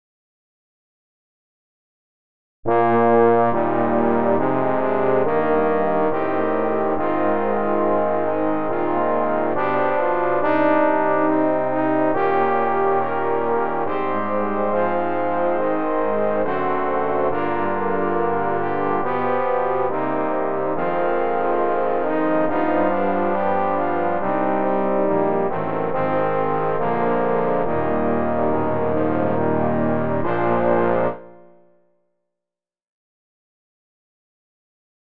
for trombone choir
Instrumentation: 6 tbns.